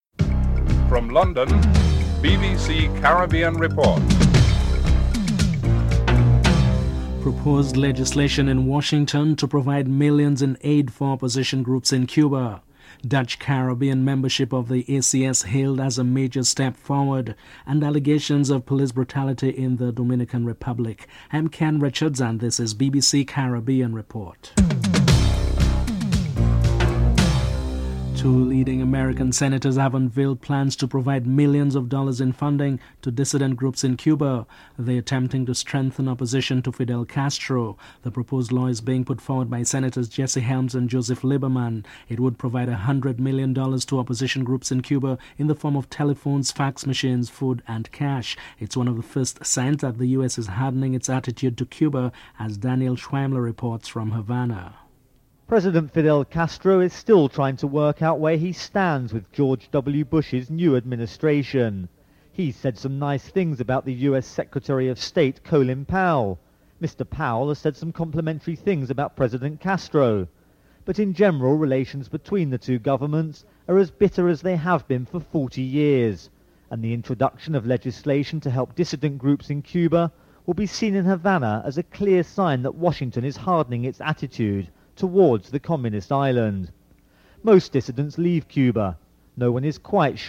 1. Headlines (00:00-00:27)
3. Dutch Caribbean membership of the ACS is hailed as a major step forward. Caricom Chief Negotiator Sir Shridath Ramphal and Former Prime Minister Eugenia Charles are interviewed (05:18-08:16)